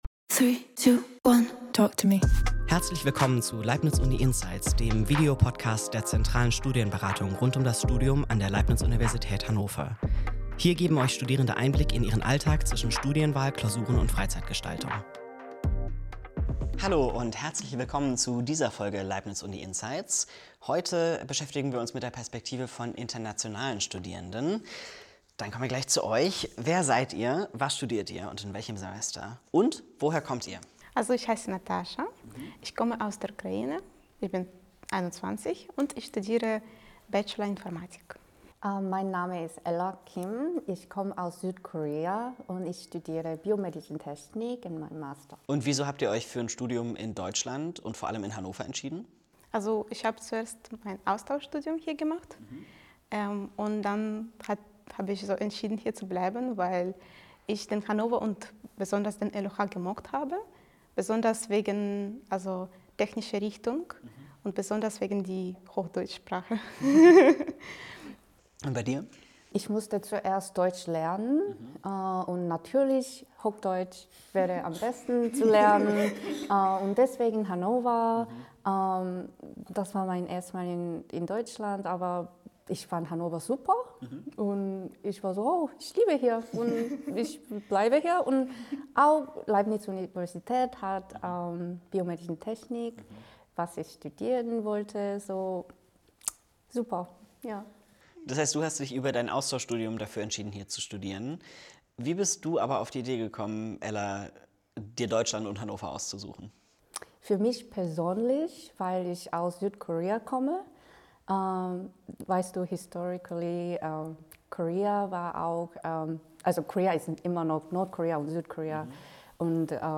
Die beiden erzählen, warum sie sich für ein Studium in Deutschland entschieden haben – und warum sie die Wahl jederzeit wieder so treffen würden. Gleichzeitig geht es auch um die Herausforderungen: von organisatorischen und bürokratischen Hürden über kulturelle Unterschiede bis zu den Kosten des Studiums. Im Gespräch wird deutlich, was das Studieren in Deutschland besonders macht, wie es sich vom Uni-Alltag in ihren Heimatländern unterscheidet und was sie an Hannover und der LUH am meisten schätzen.